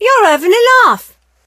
rosa_hit_02.ogg